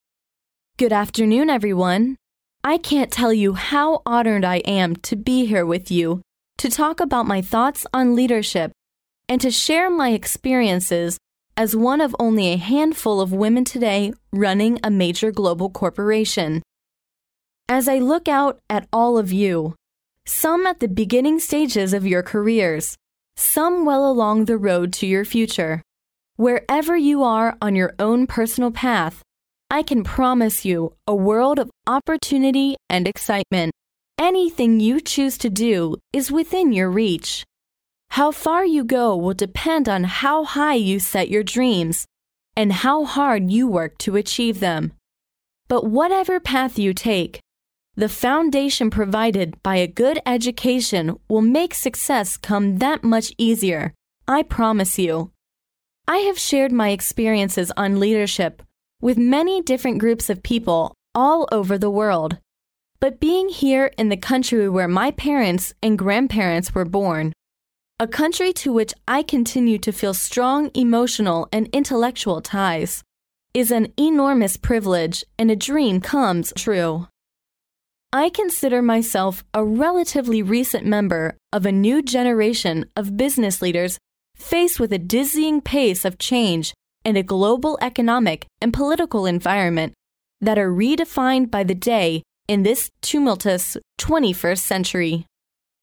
借音频听演讲，感受现场的气氛，聆听名人之声，感悟世界级人物送给大学毕业生的成功忠告。同时，你可以借此机会跟世界顶级人物学习口语，听他们的声音，模仿地道的原汁原味的腔调。